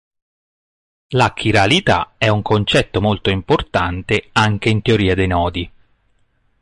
Pronúnciase como (IPA) /ˈnɔ.di/